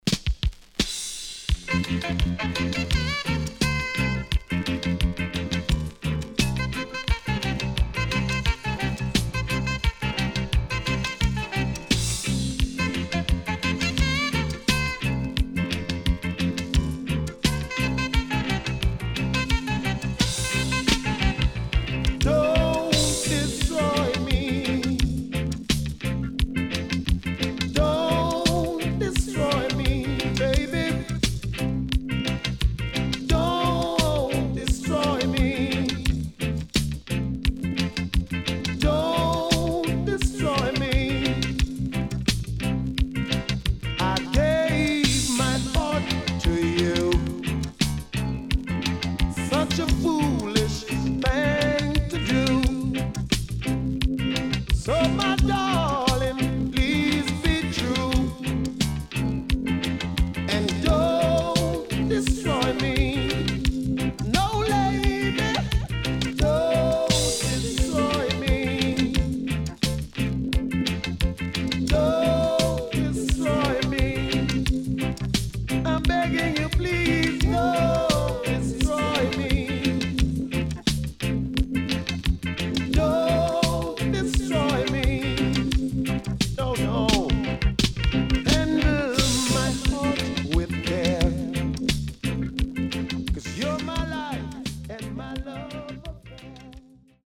HOME > DISCO45 [VINTAGE]  >  SWEET REGGAE
SIDE A:うすいこまかい傷ありますがノイズあまり目立ちません。